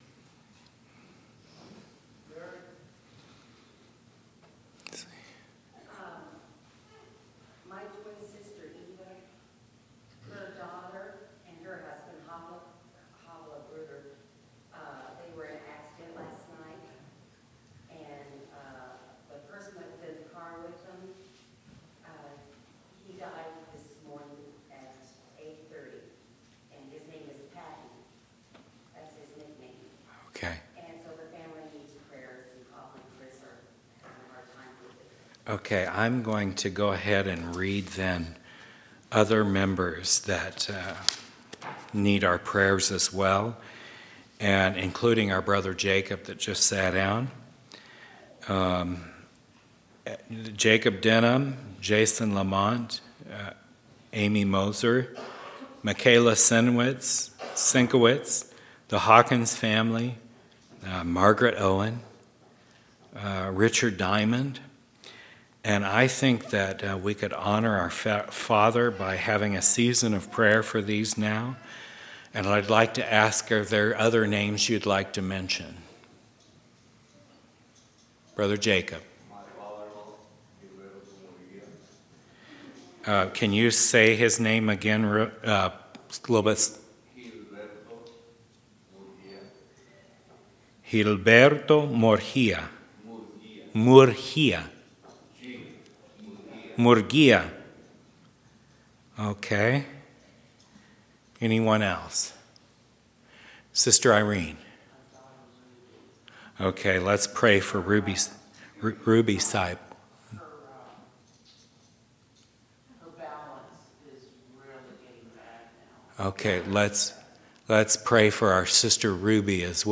6/19/2011 Location: Temple Lot Local Event